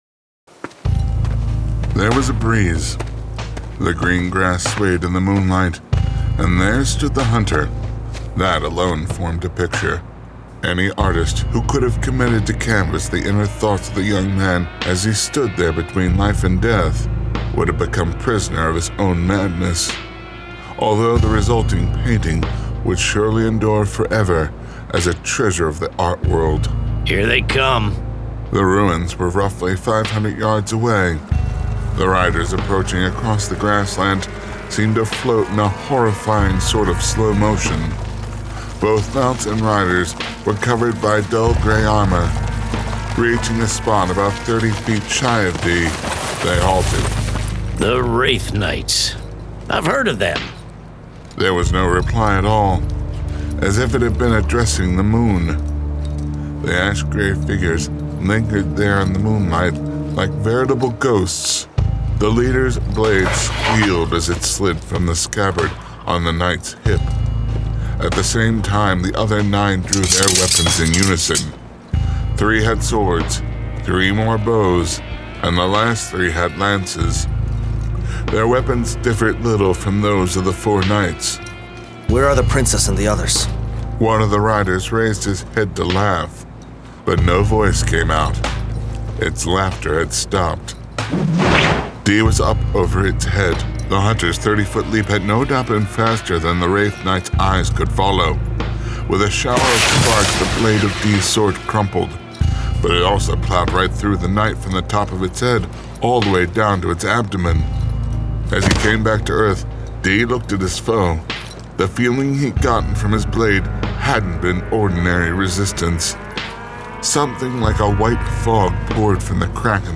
Full Cast. Cinematic Music. Sound Effects.
[Dramatized Adaptation]
Genre: Fantasy
Adapted from the novel and produced with a full cast of actors, immersive sound effects and cinematic music!